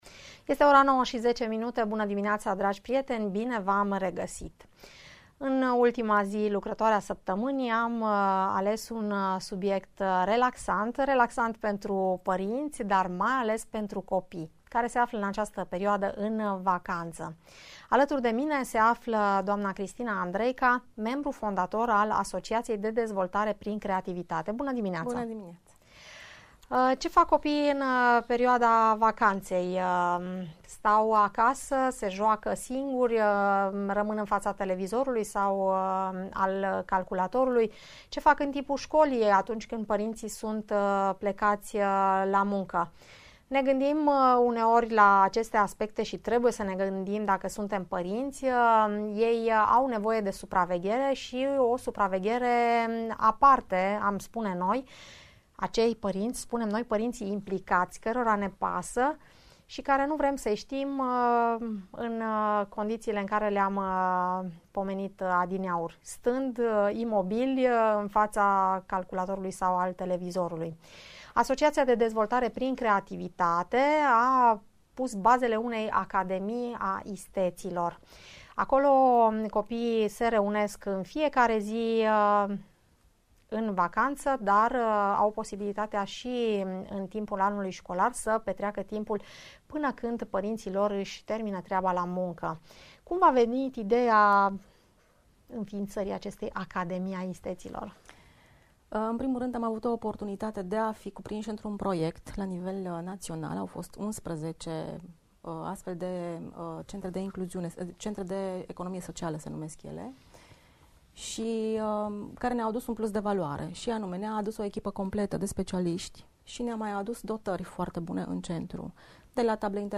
La ” Parerea ta” o discutie despre programul de vacanta al copiilor si despre sistemul after school